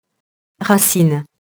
racine [rasin]